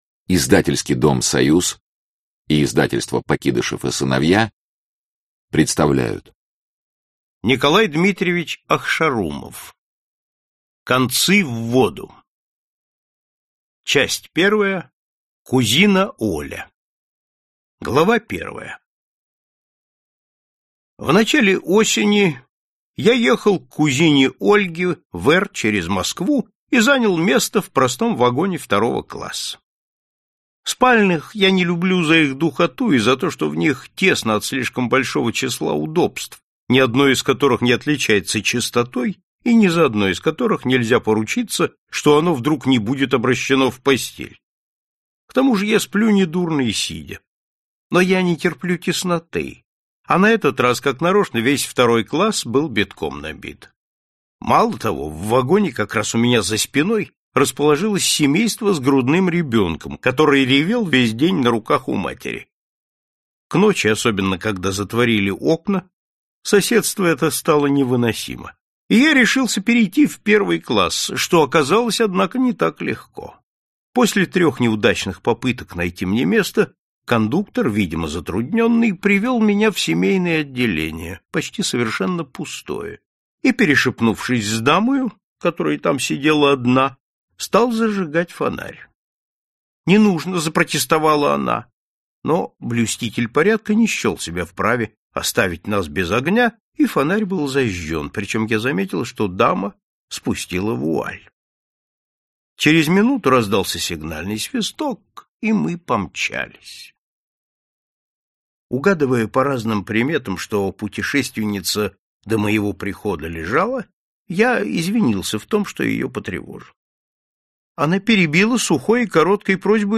Аудиокнига Концы в воду | Библиотека аудиокниг